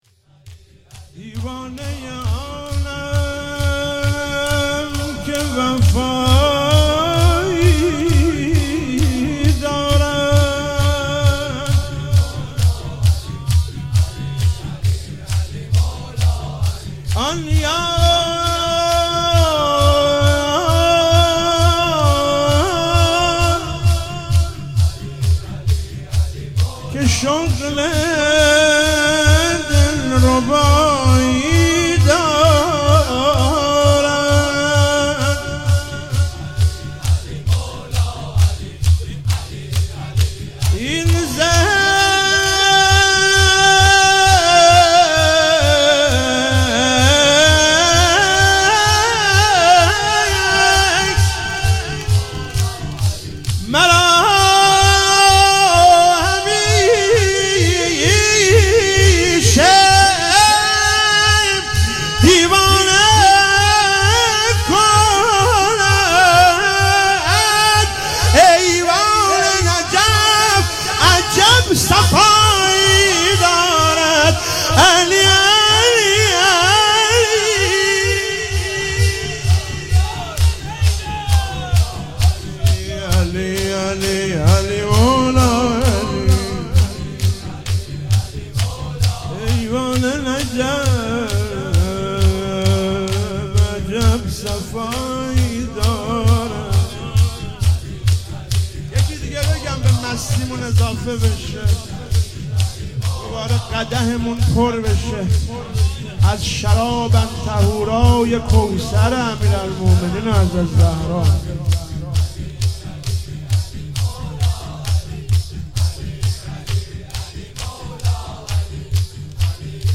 شب هشتم محرم 97 - شور - دیوانه ی آنم که وفایی دارد